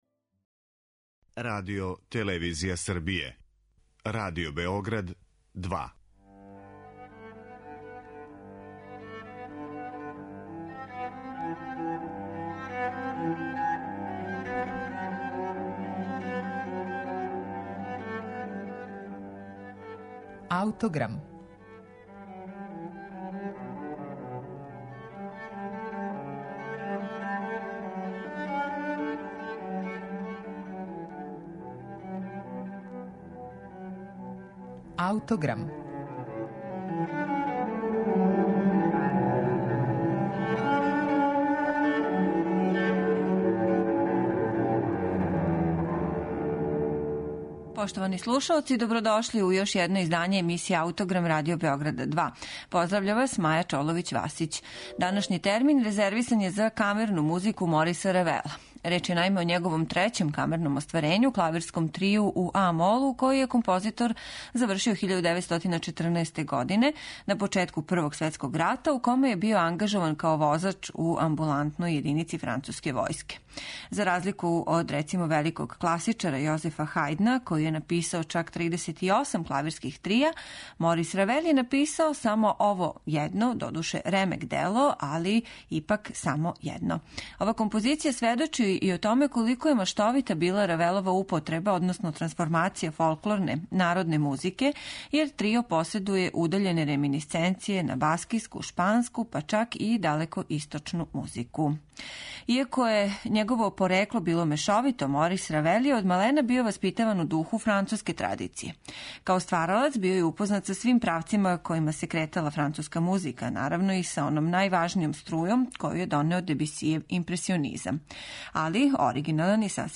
Клавирски трио
Трио, наиме поседује удаљене реминисценције на баскијску, шпанску и чак далекоисточну музику. Слушаћемо га у извођењу ансамбла Trio di Parma.